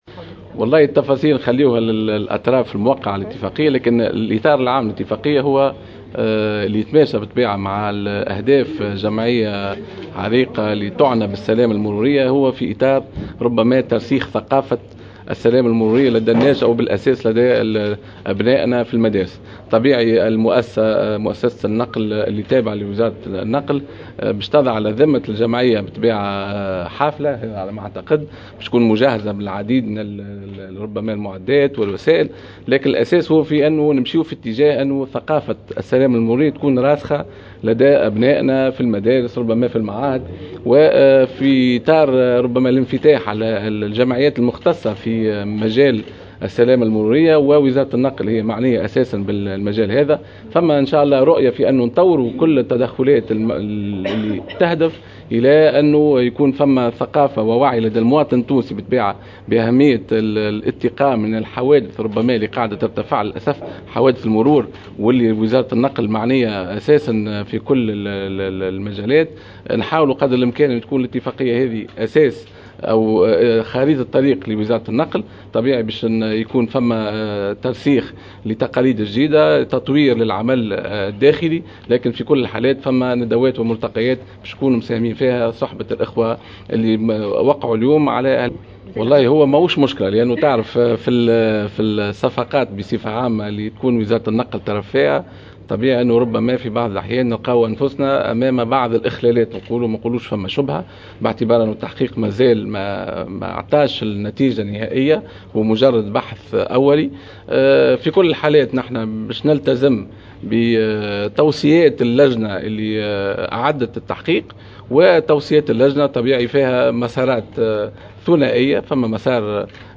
قال وزير النقل رضوان عيارة في تصريح لمراسلة الجوهرة "اف ام" اليوم الجمعة 22 سبتمبر 2017 إن الإطار العام للاتفاقية التي أمضتها الوزارة مع الجمعية التونسية للوقاية من حوادث الطرقات يتماشى مع أهداف جمعية عريقة التي تعنى بالسلامة المرورية وهو ترسيخ ثقافة المواطنة السلامة المروية لدى الناشئة.